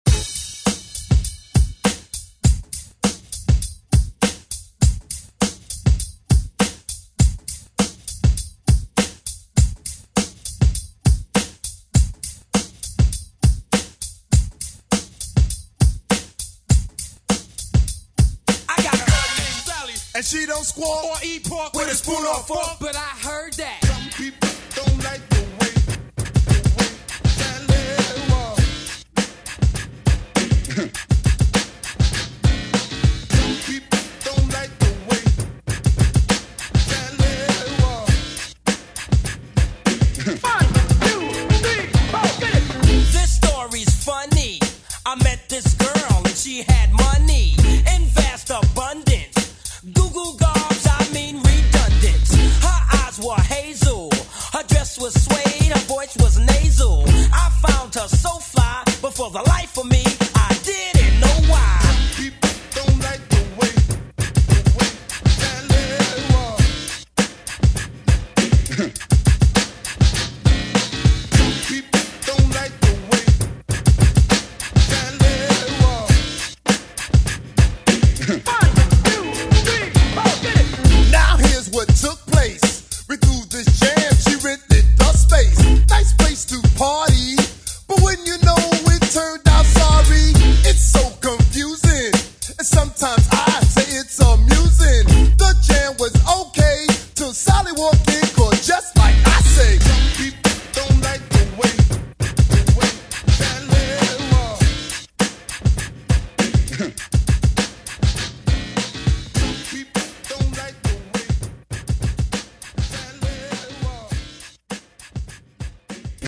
Genre: 80's
Clean BPM: 123 Time